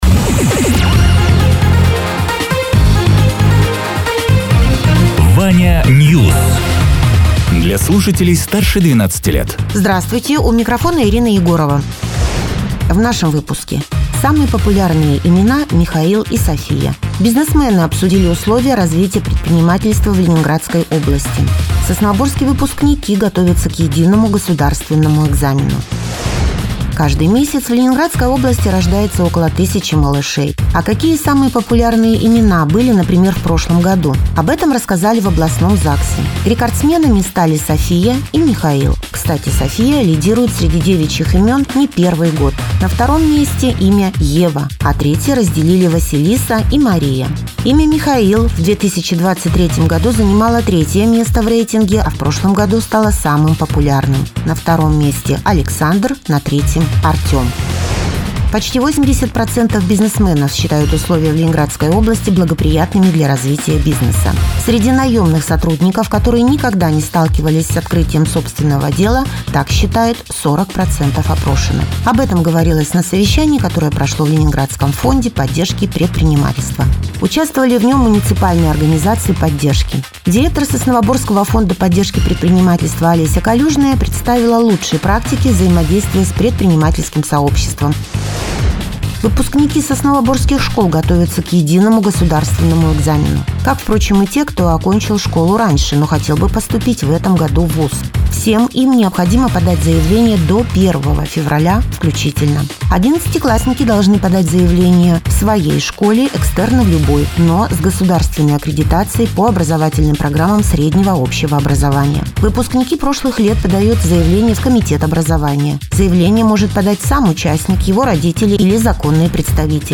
Радио ТЕРА 18.01.2025_10.00_Новости_Соснового_Бора